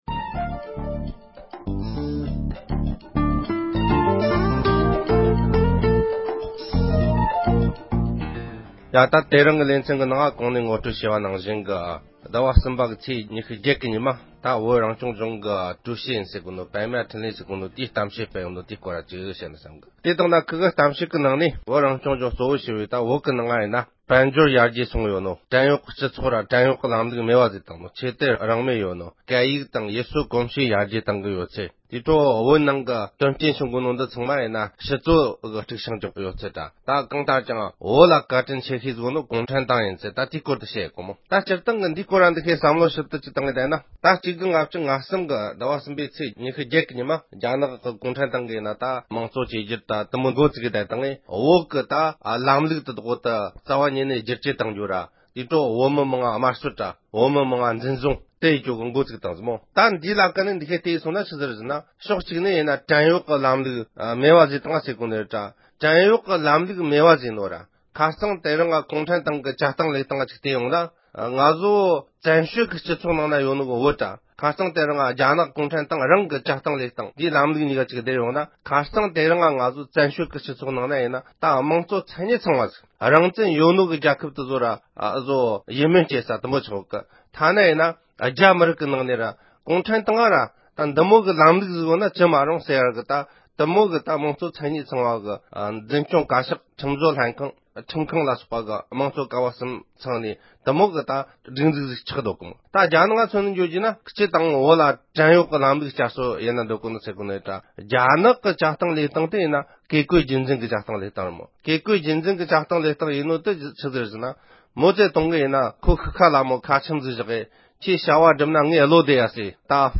དཔྱད་བརྗོད